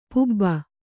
♪ pubba